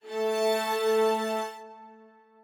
strings10_12.ogg